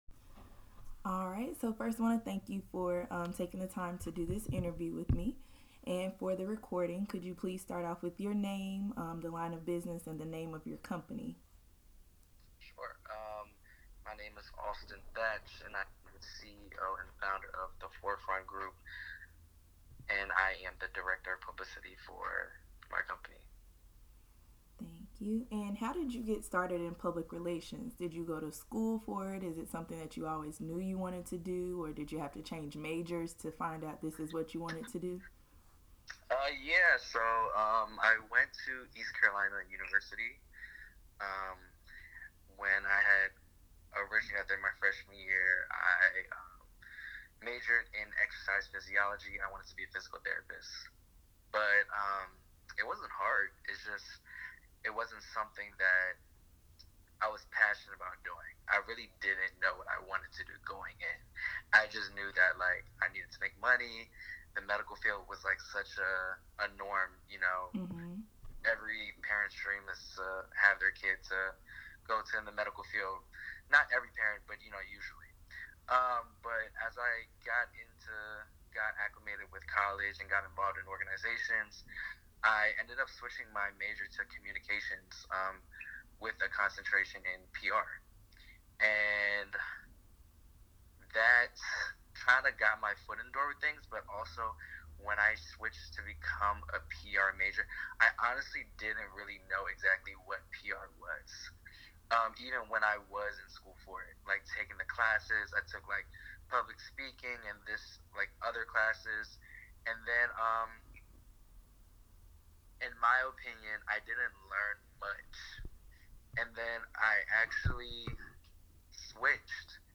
He shares his experience in Public Relations and entertainment. These are the resources I used to help guide me through this interview.